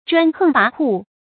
注音：ㄓㄨㄢ ㄏㄥˋ ㄅㄚˊ ㄏㄨˋ
專橫跋扈的讀法